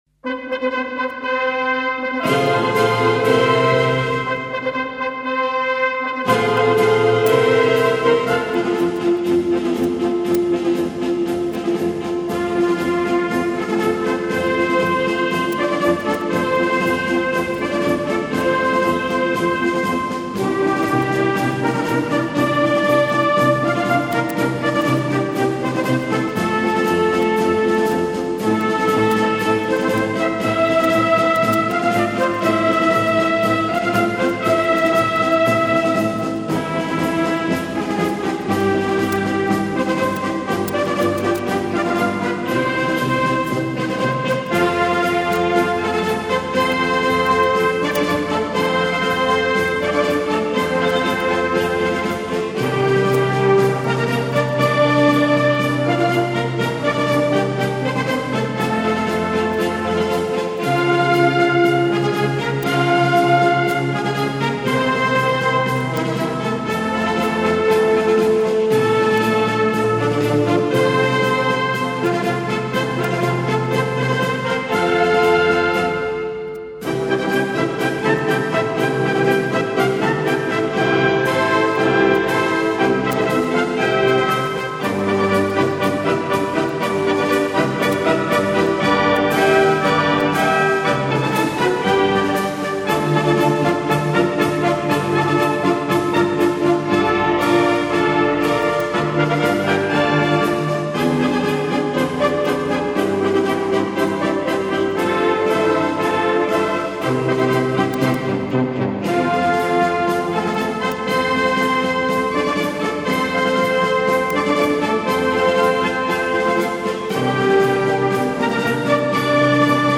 allego anche la marcetta del giuramento eseguita dalla Julia (